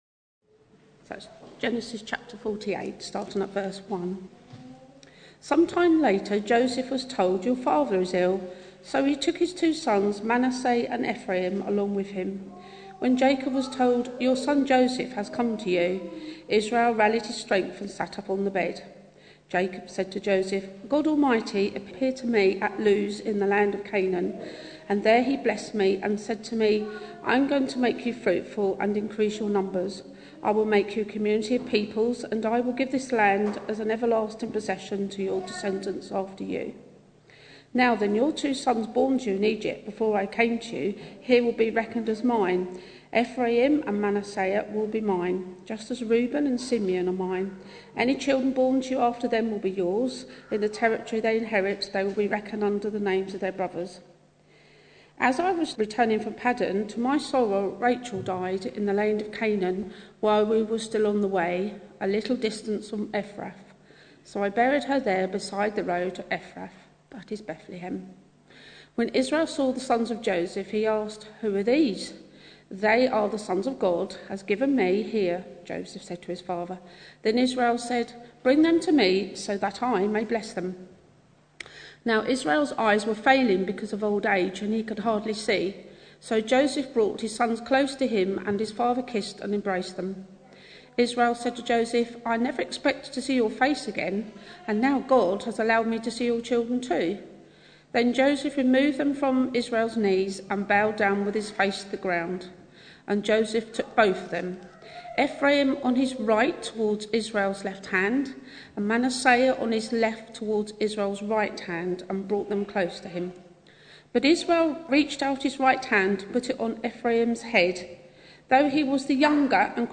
Passage: Genesis 48 Service Type: Sunday Morning Bible Text